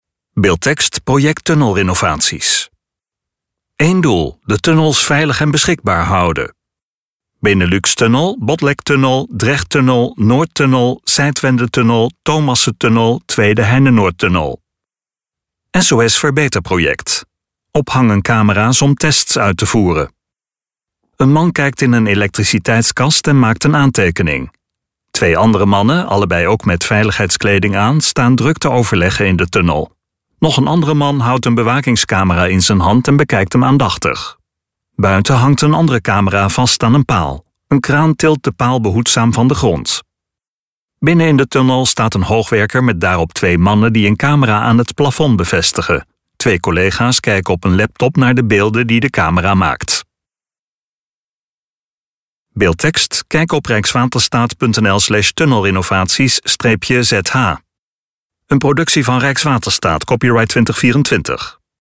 STEVIGE MUZIEK DE STEVIGE MUZIEK GAAT OVER IN STUWENDE MUZIEK (Een man kijkt in een elektriciteitskast en maakt een aantekening.